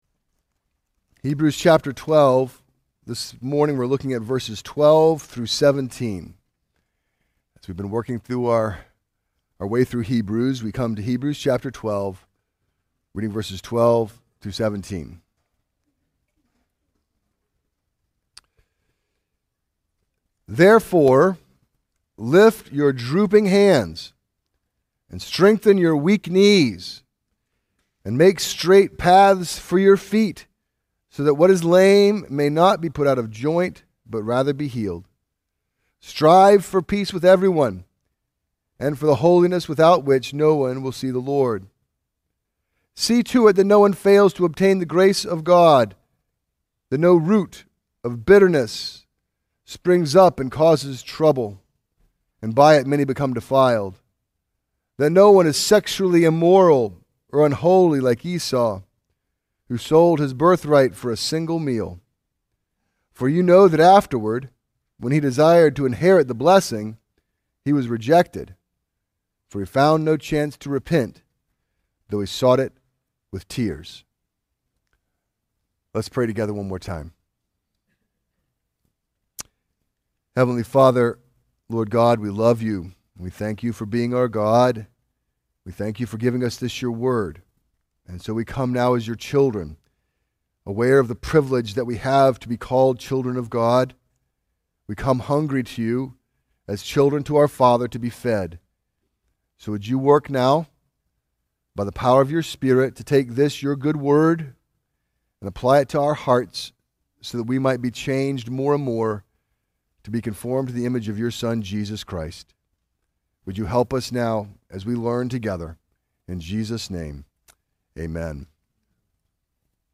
Sermons – Redeemer Community Church